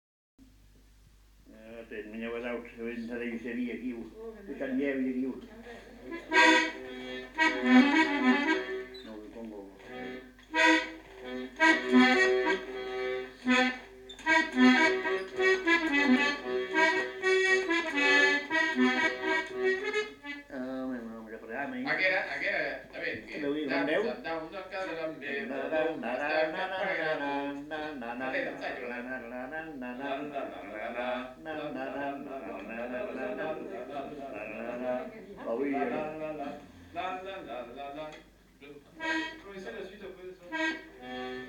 Dançam dauna aniram beure (fredonné)
Aire culturelle : Petites-Landes
Lieu : Lencouacq
Genre : chant
Effectif : 1
Type de voix : voix d'homme
Production du son : fredonné
Danse : rondeau